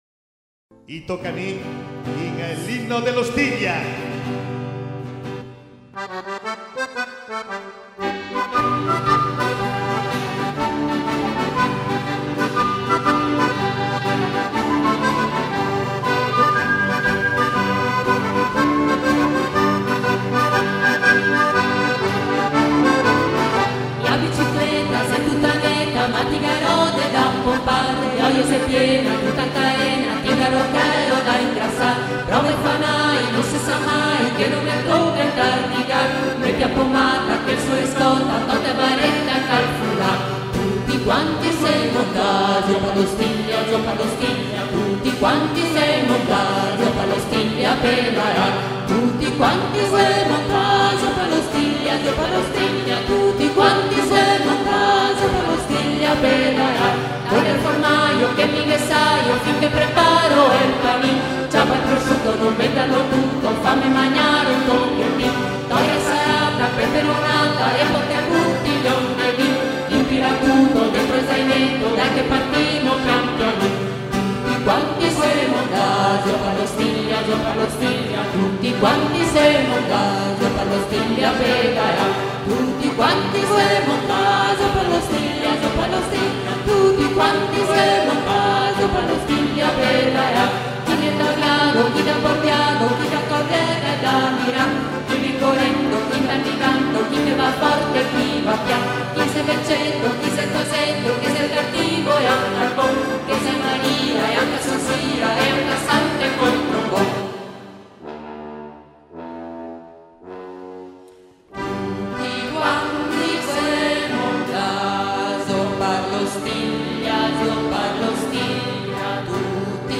Inno dell'OstigliaCiclabile ralizzato dai TOCA MI per il Comitato OstigliaCiclabile
registrazione live eseguita durante il concerto